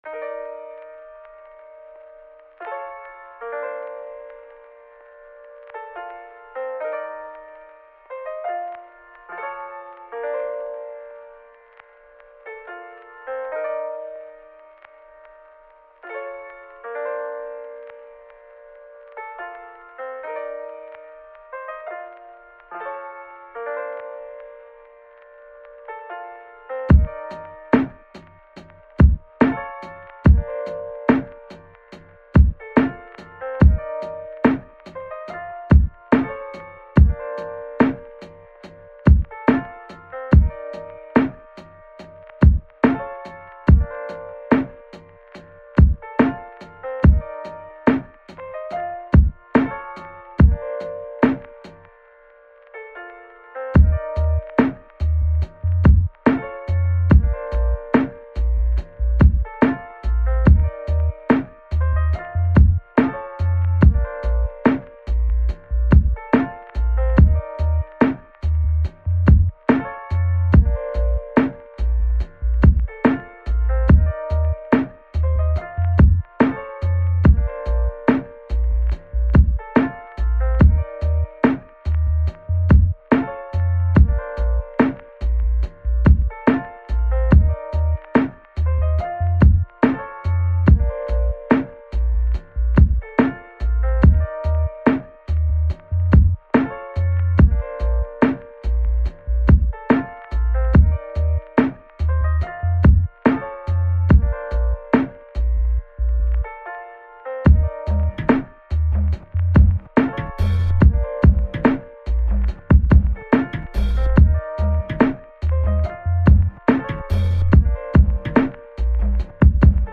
moar lo-fi for your eardrums~